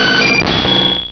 Cri d'Airmure dans Pokémon Rubis et Saphir.